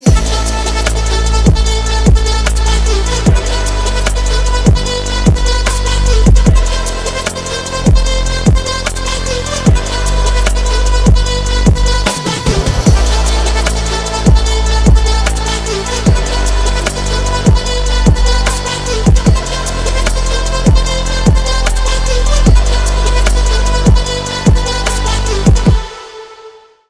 • Качество: 320, Stereo
красивые
атмосферные
Electronic
без слов
Trap
восточные
Атмосферный трэп